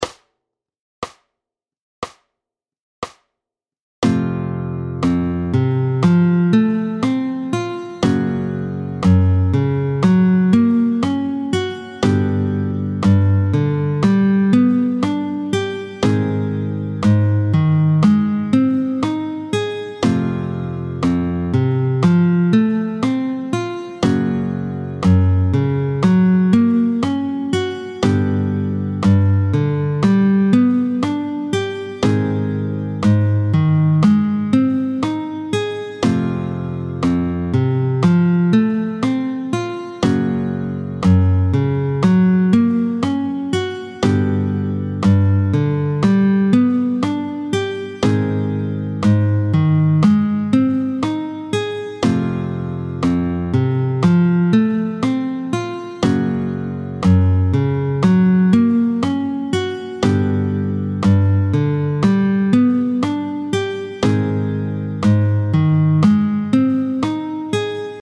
28-01 Les barrés à base de « Mi », tempo 60